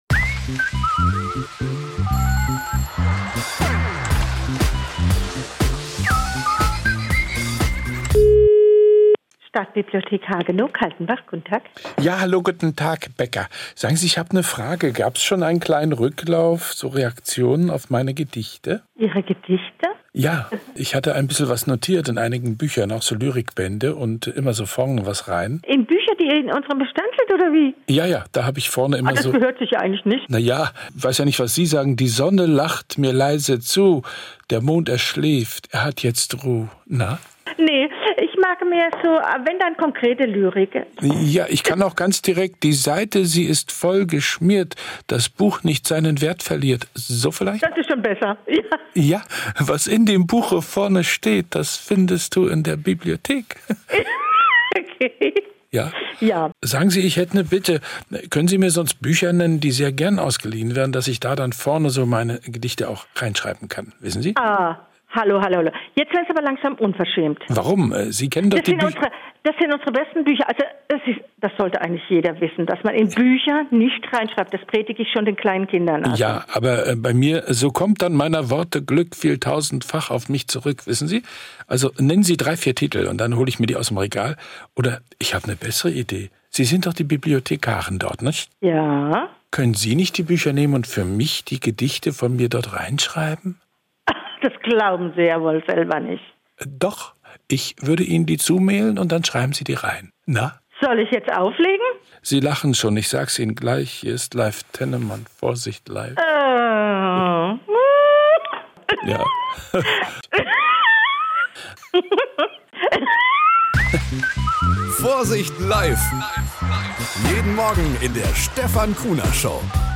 Morgenandacht bei NDR 1 Radio MV - 15.10.2024